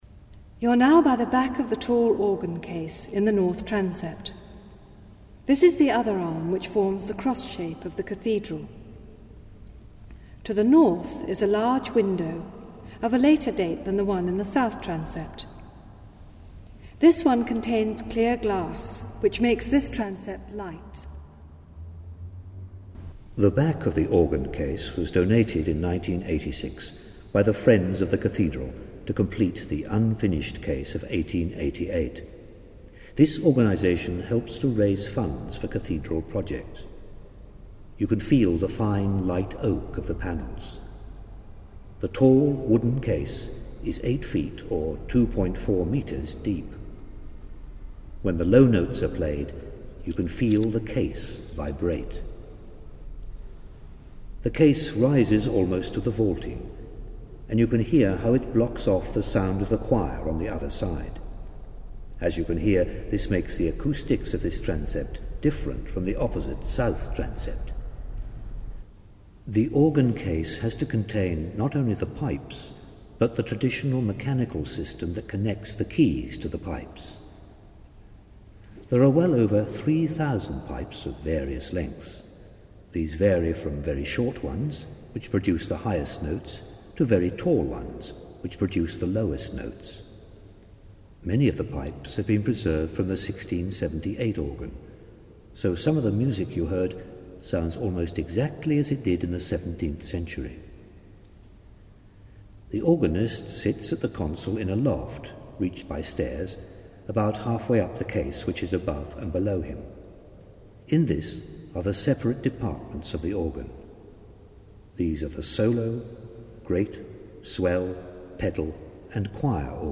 An Acoustic Fingerprint Guide of Chichester Cathedral - 7: North Transept and the Organ
7-north-transept.mp3